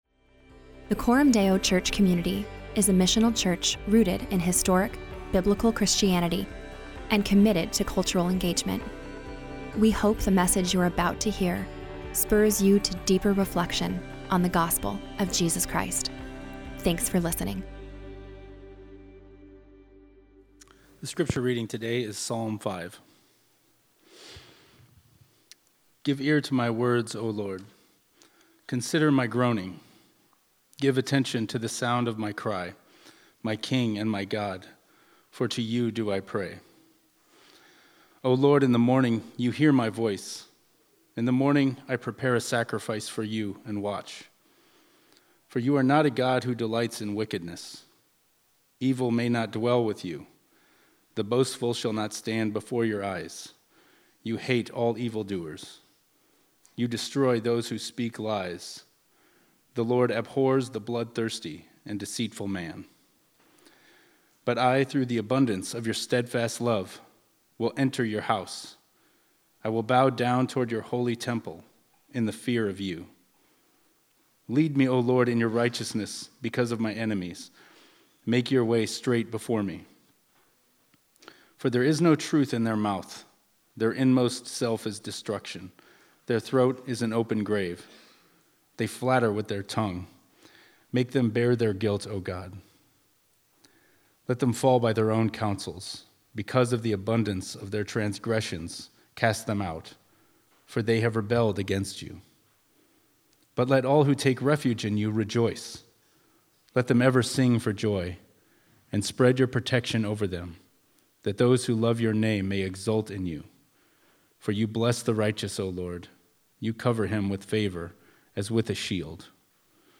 So every summer, the people of Coram Deo dwell in the Psalms - the worship songbook of God’s people - to learn the language and practices of Christian worship. This preaching series is a progressive journey